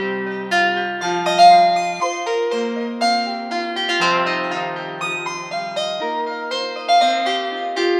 Cinnamon Guitar Bells Whatever
描述：C pentatonic minor scale
标签： 120 bpm LoFi Loops Bells Loops 1.35 MB wav Key : Cm Cubase
声道立体声